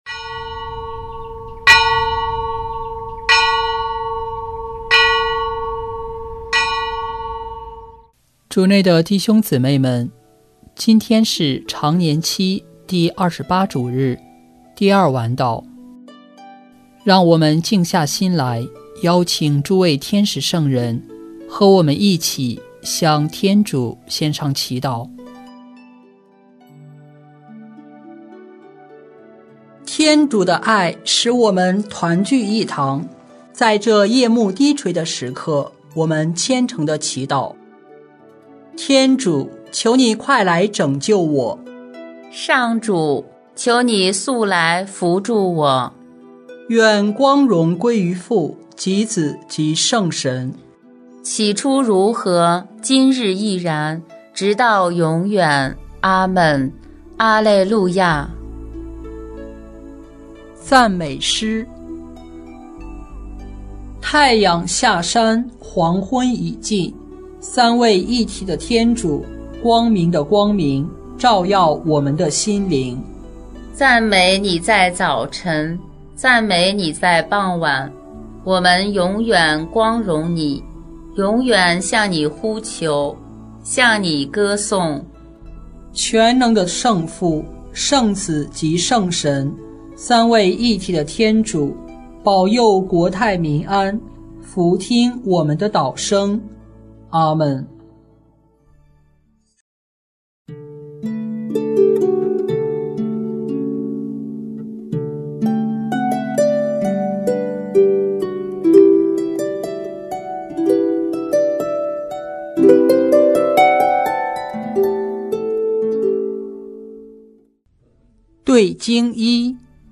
圣咏吟唱 圣咏 109:1-5,7 默西亚是君王，也是祭司 “基督必须为王，直到把一切仇敌屈服在他的脚下。”